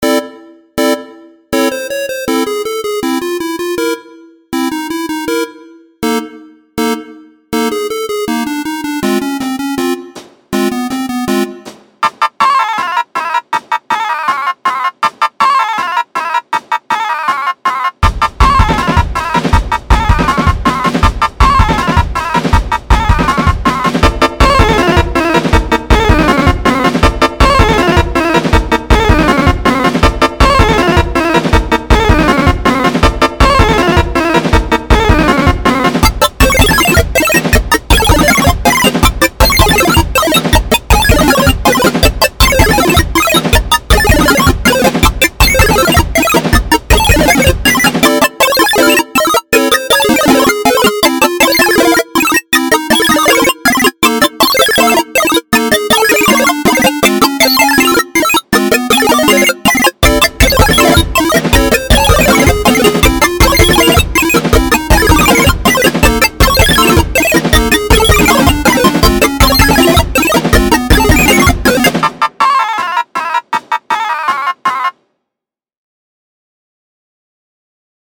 More fun videogamey stuff. I AM PROUD OF THE DRUMS ON THIS!